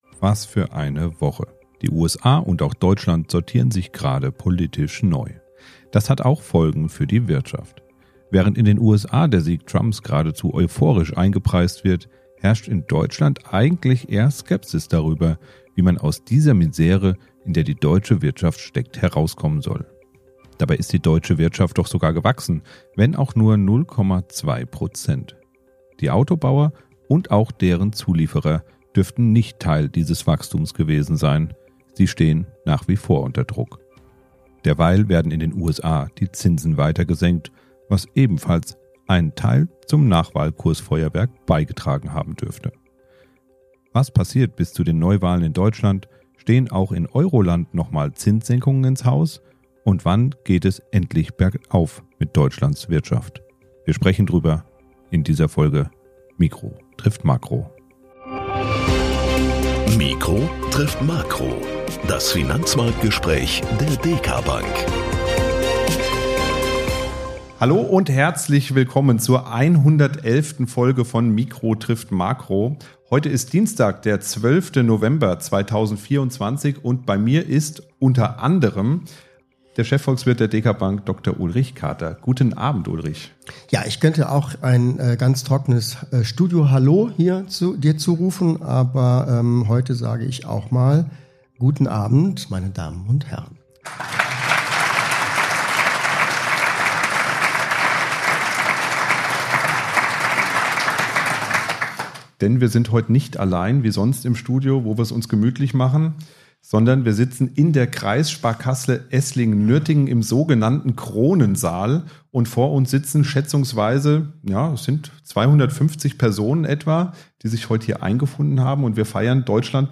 Live aus der KSK Esslingen-Nürtingen